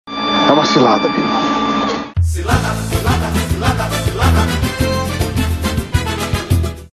No final, trecho da música Cilada do Grupo Molejo.